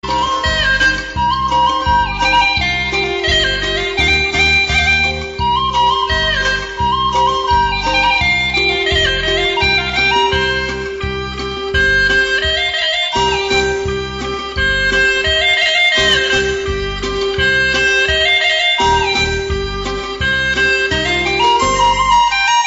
pan-flute_27694.mp3